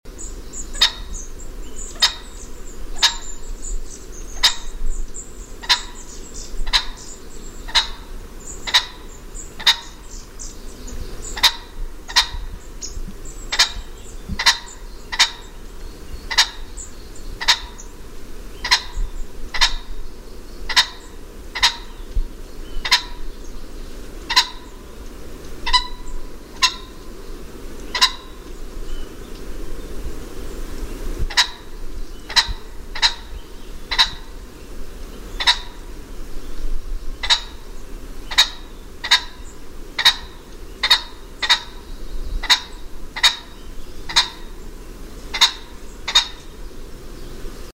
Звуки фазана
На этой странице собраны разнообразные звуки фазанов – от характерного квохтания до резких тревожных криков.